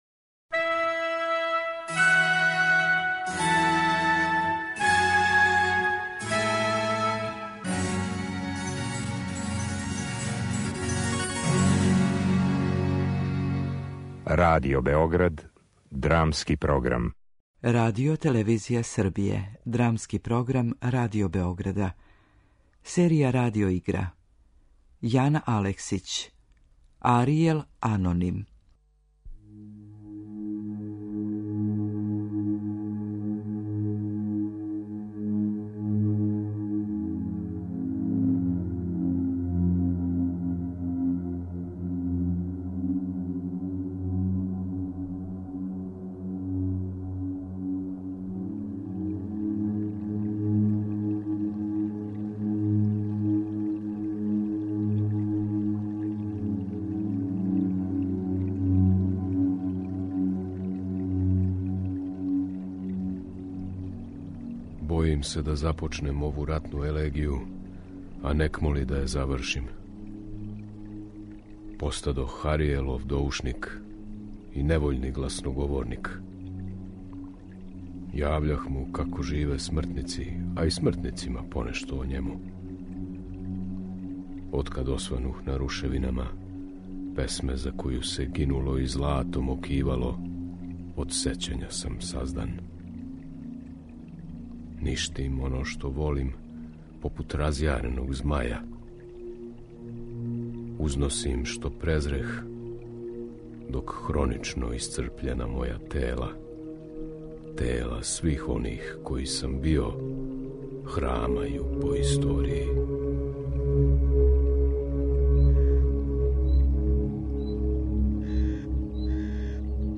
Радио игра